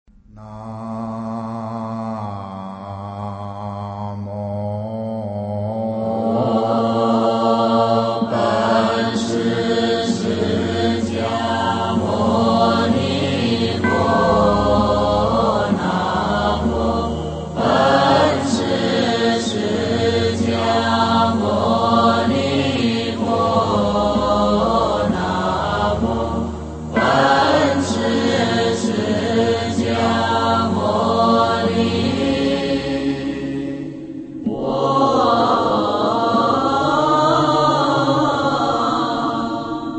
本师释迦牟尼佛圣号（长时版） - 佛乐诵读